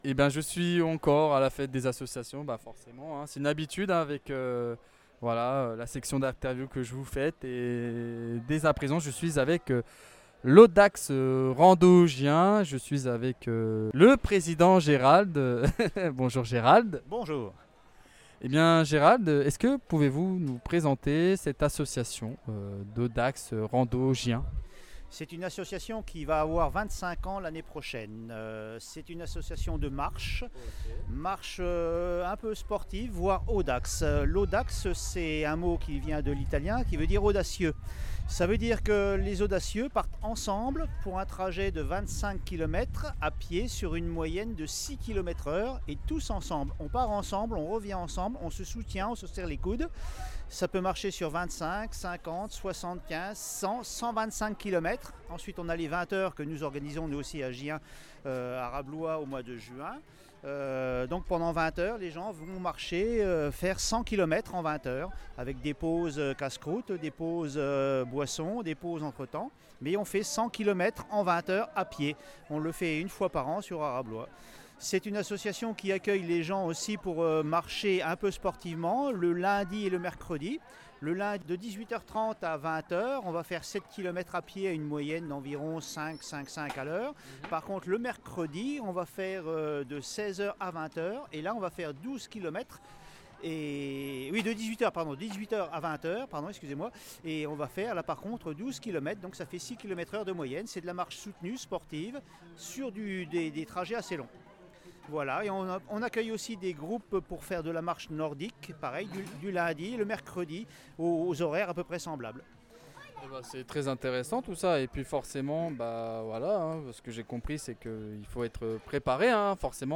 Fête des associations de Gien 2025 - Audax Rando Gien